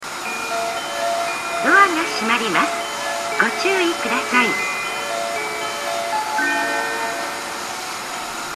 ○発車メロディー○
発車メロディーフルコーラスです。雑音が入っているので音量にご注意ください。また発車メロディーの音量が大変小さいです。